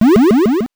powerup_3.wav